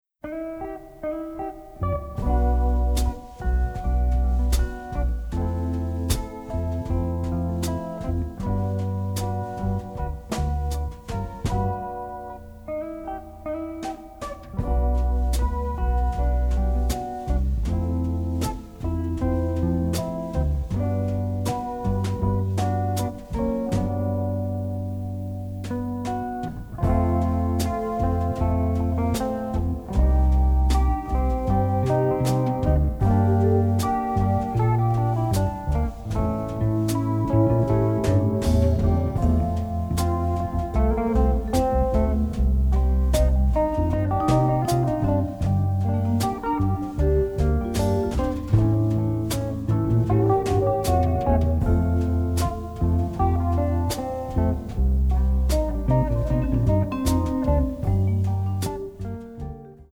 Italian masterpiece of psychedelia!